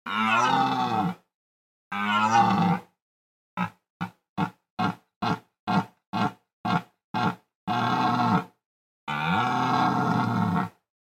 На этой странице собраны натуральные звуки оленей: от нежного фырканья до мощного рева в брачный период.
Гул алого оленя